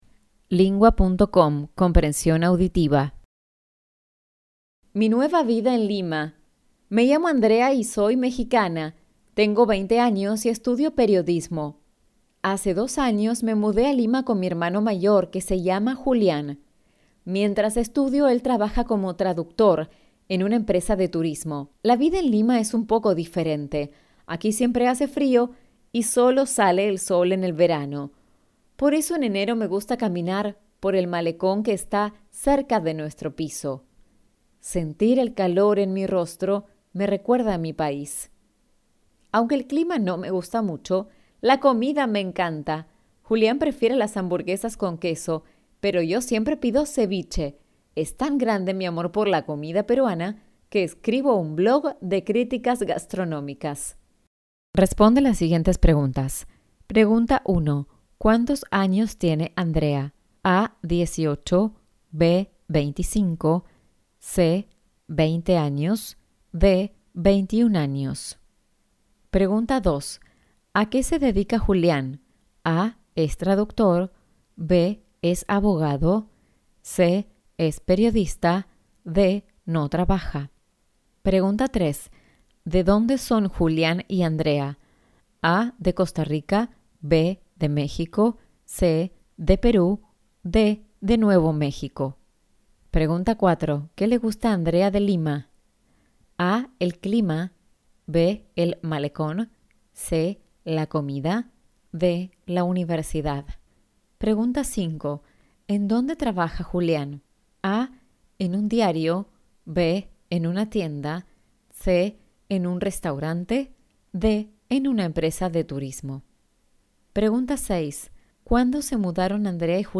Argentyna
Hiszpania